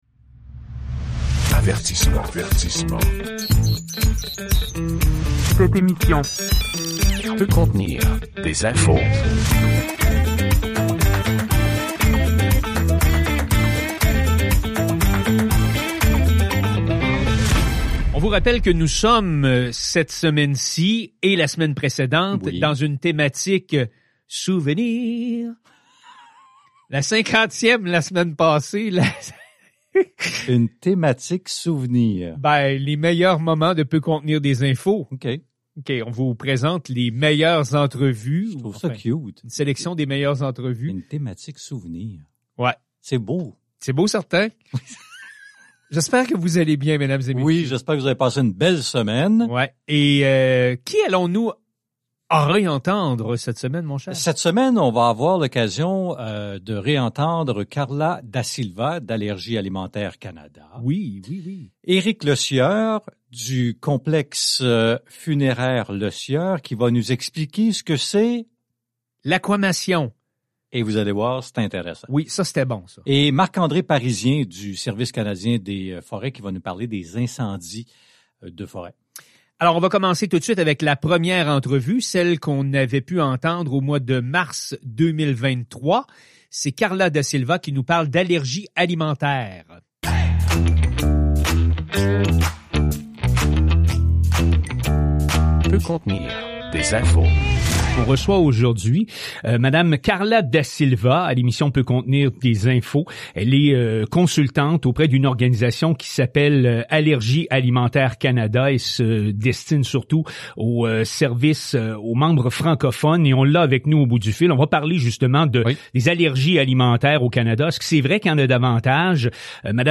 Cette 51e émission spéciale de "Peut contenir des infos" est une rétrospective renfermant quelques-unes des meilleures entrevues réalisées depuis le commencement de l'émission à l'automne 2022.